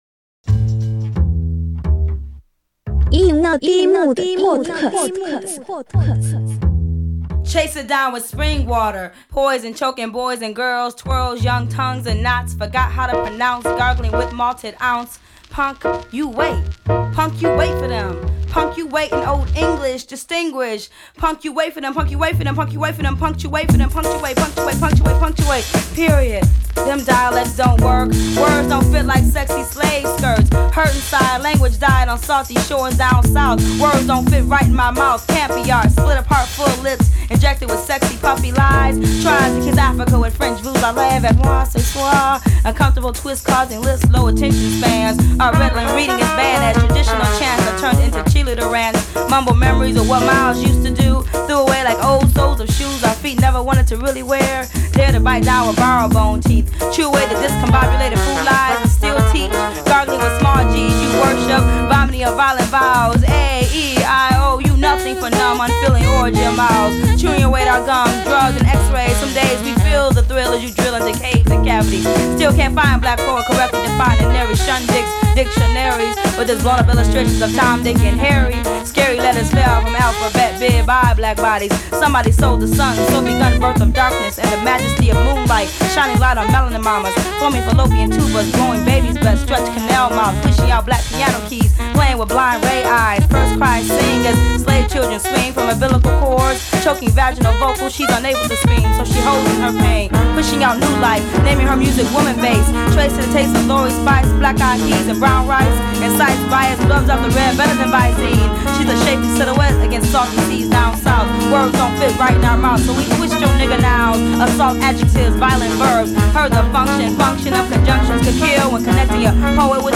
It’s warm, cozy